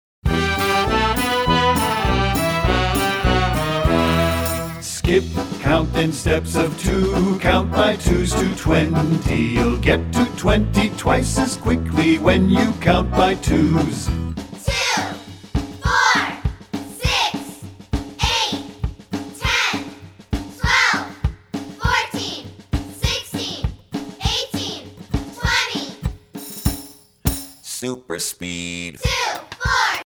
- Mp3 Vocal Song Track